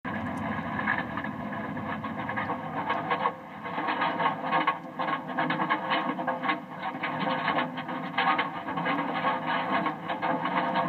AM Mitschnitt Eisenbahngeräusche/train noise